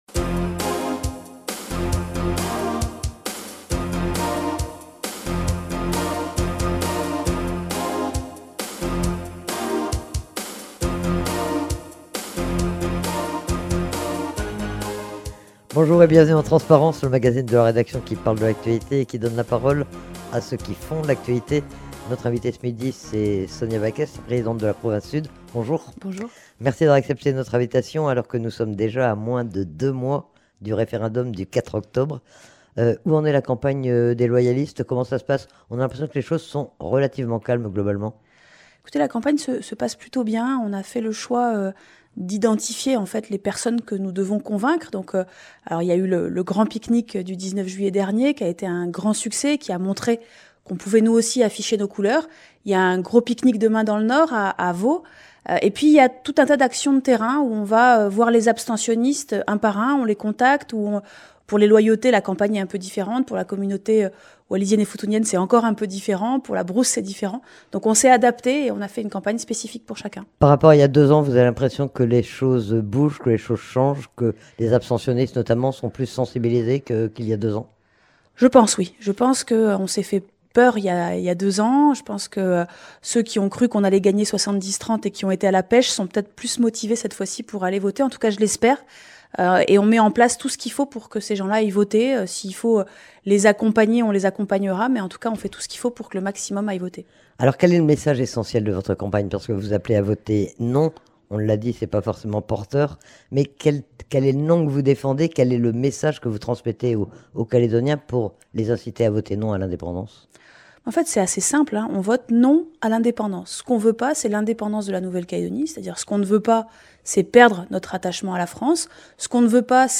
Sonia Backès invitée de Transparence.